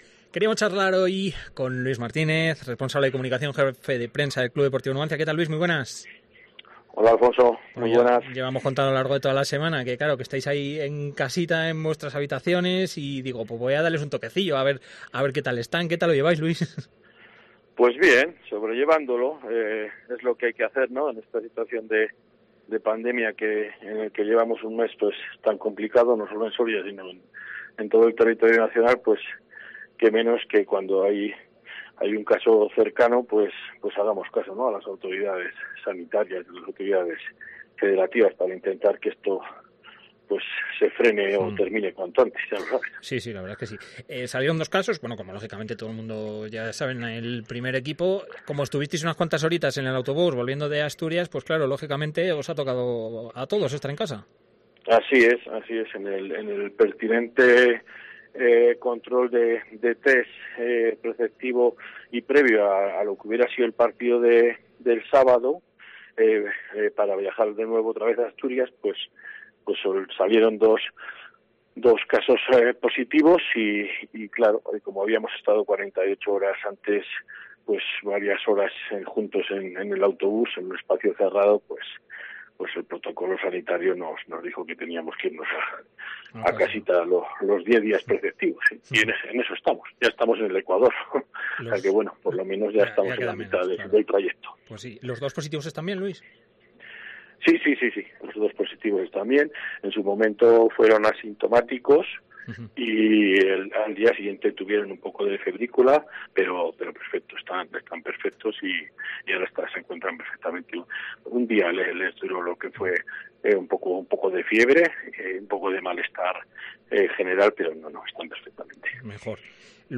Deportes COPE Soria Entrevista